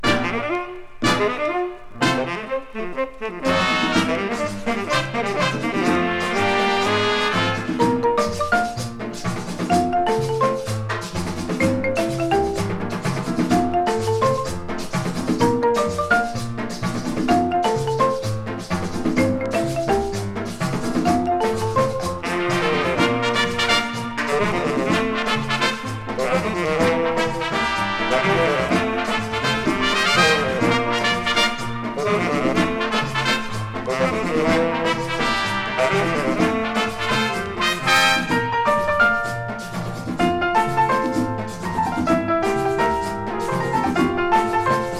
熱さもあり、涼やかさもあり、流暢でキレのある演奏はバンドの充実度が高く魅力いっぱいです。